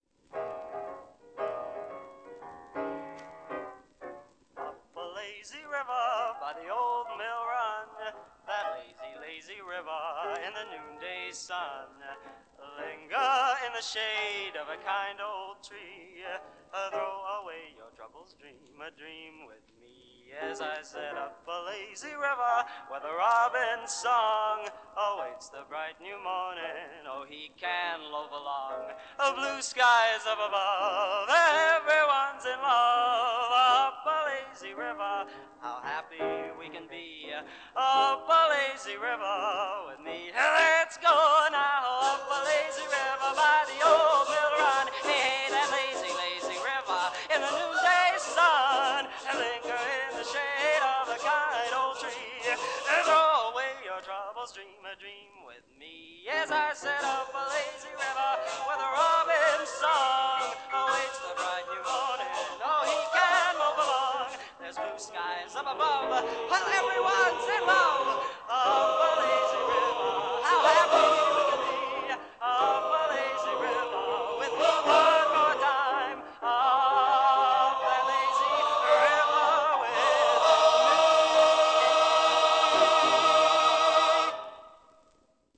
Genre: Popular / Standards | Type: Solo |Studio Recording